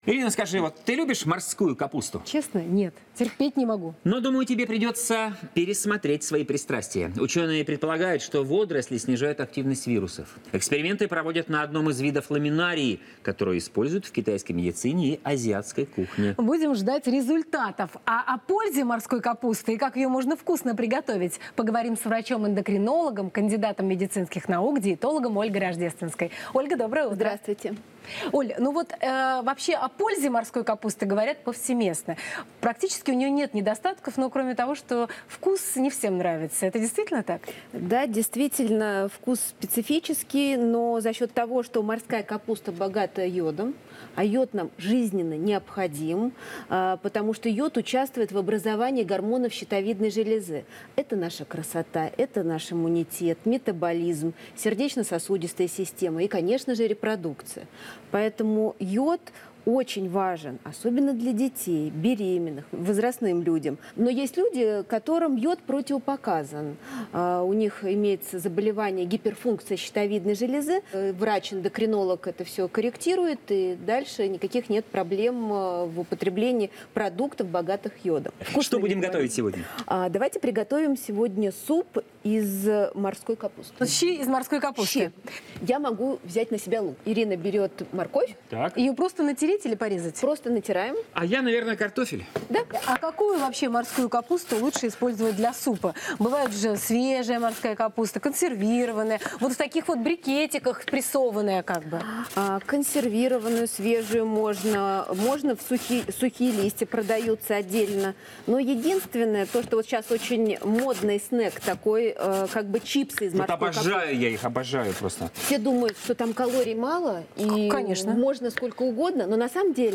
Источник: телеканал ТВЦ